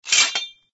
TL_rake_pickup_only.ogg